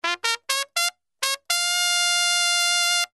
Звуки музыкальной трубы: Триумфальная мелодия трубой весть о победе над врагом